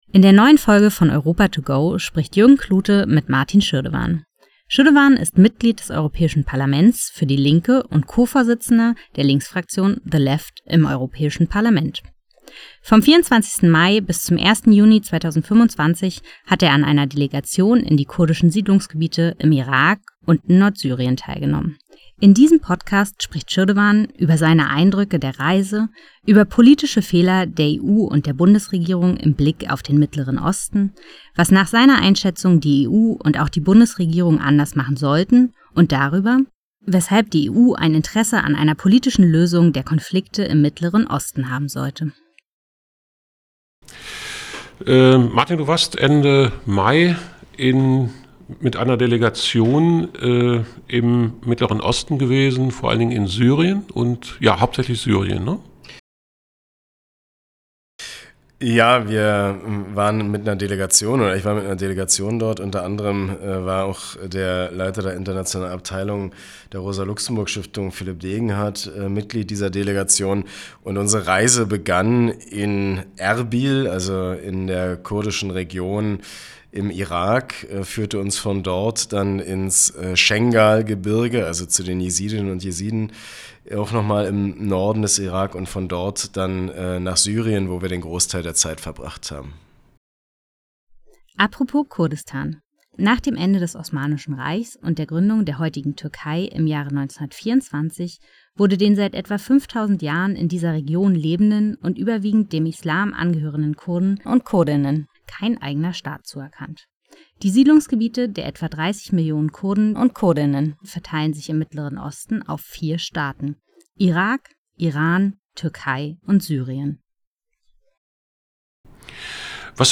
MdEP Martin Schirdewan im Gespräch mit Europa to go über seine Reise in den Irak und nach Nord-Syrien